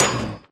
Sound / Minecraft / mob / blaze / hit1.ogg
hit1.ogg